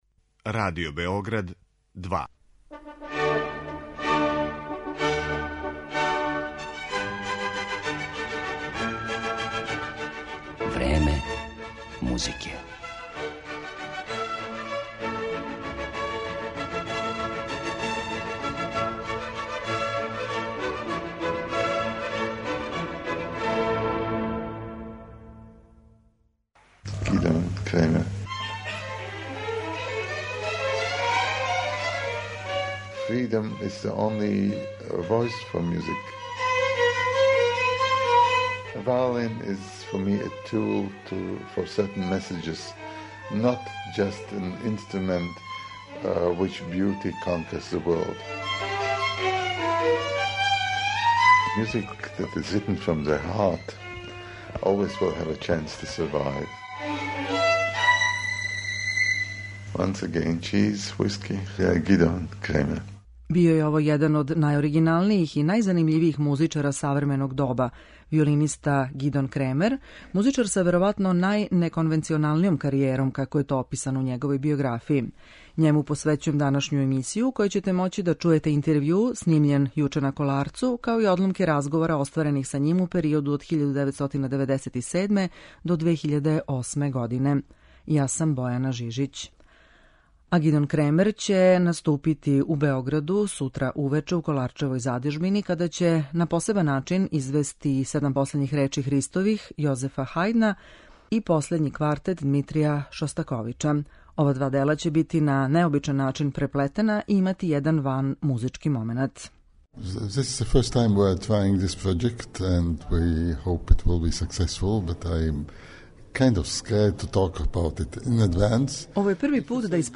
Њему је посвећена данашња емисија у којој ћемо овог славног солисту представити и кроз интервју снимљен у Београду 2. априла 2019, као и кроз одломке разговора остварених са њим у периоду од 1997. до 2008. године.